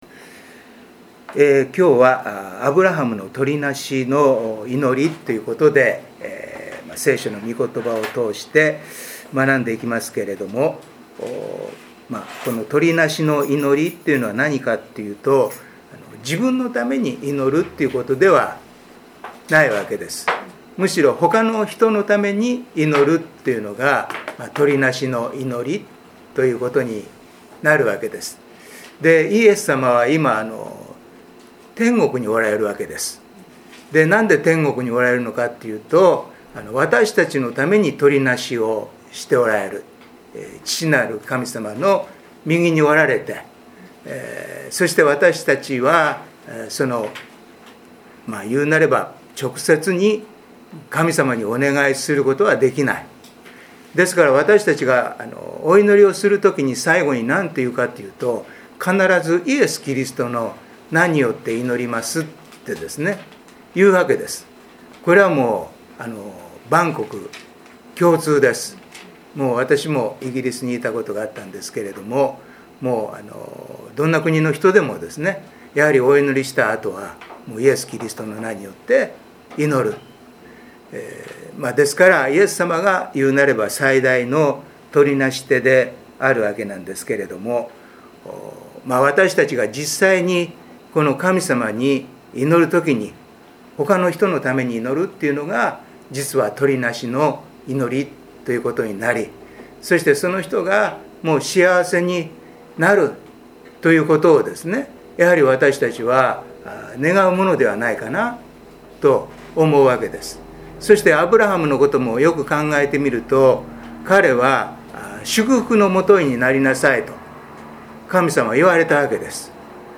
聖書研究祈祷会│日本イエス・キリスト教団 柏 原 教 会